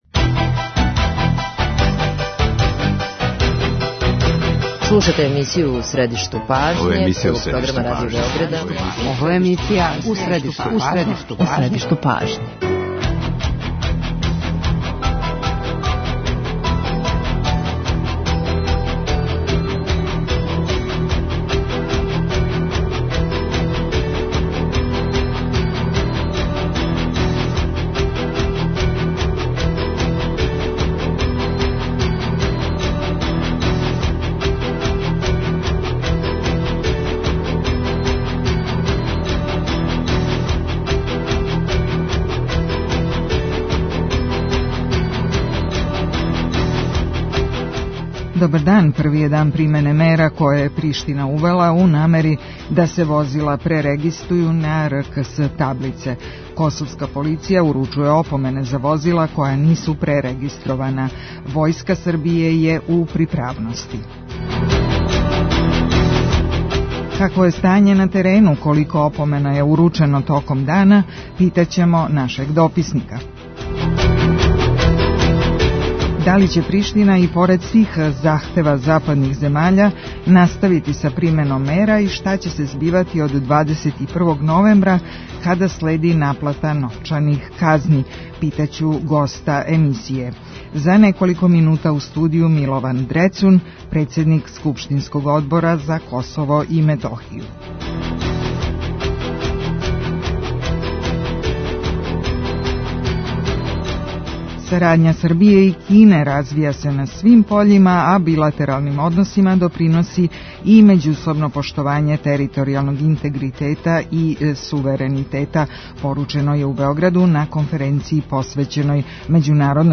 Ситуацију на Косову и Метохији и међународни контекст коментарише Милован Дрецун, председник Одбора Скупштине Србије за Косово и Метохију.